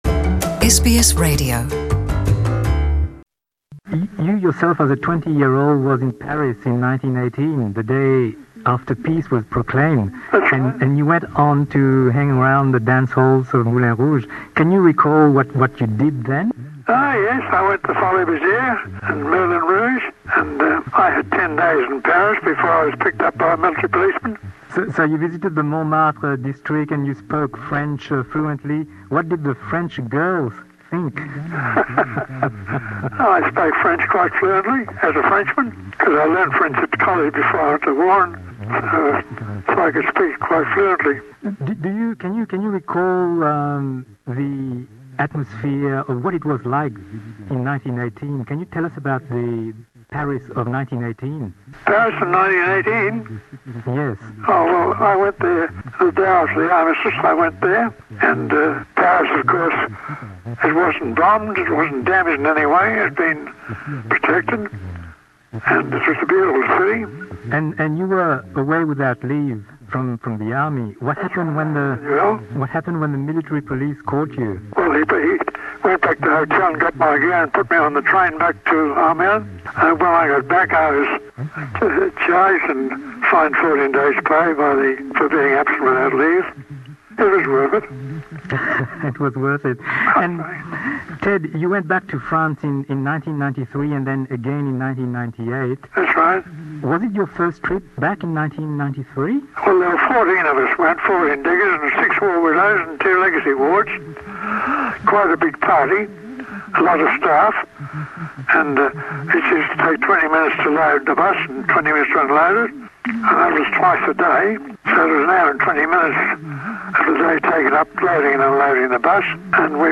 Une interview faite en 2001, juste après la sortie du film Moulin Rouge en Australie.